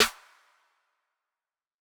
G Season Snare.wav